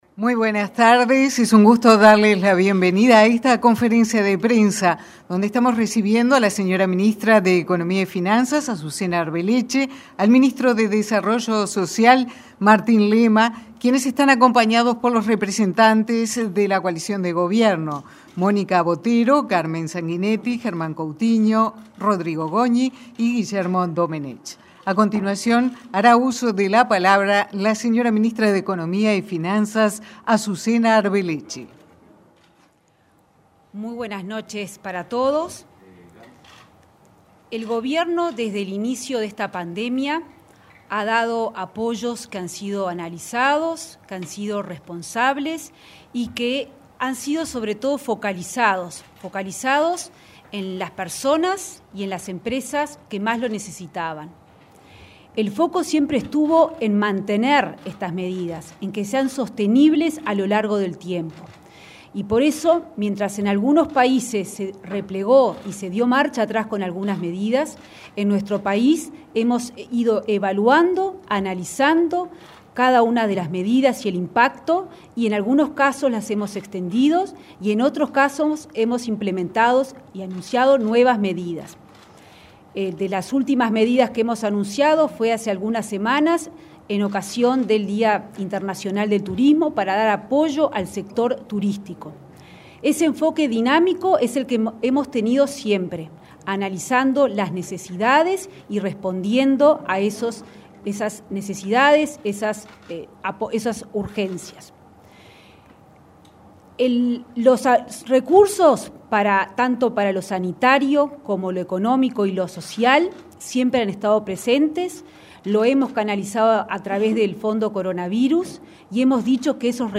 Las medidas anunciadas este jueves 28 en conferencia de prensa por parte del ministro Martín Lema y la ministra Azucena Arbeleche, junto a representantes de la coalición de gobierno, responden a una evaluación social y económica de la coyuntura del país.